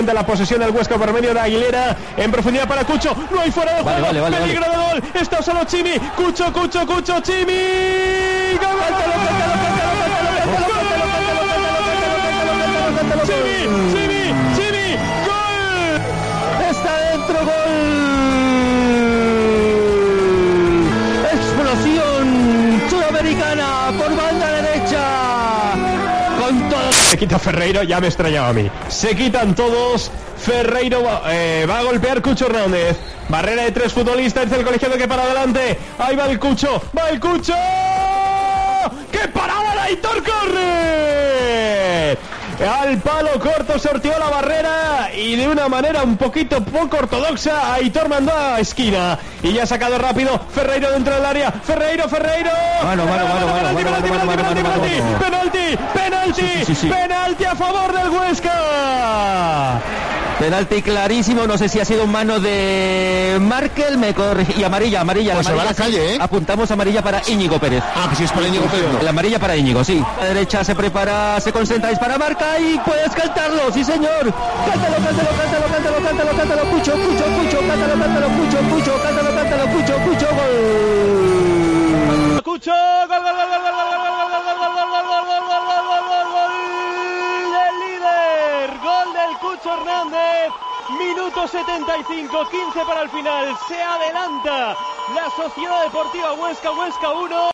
Así se contaron los goles del Huesca en la transmisión local de COPE HUESCA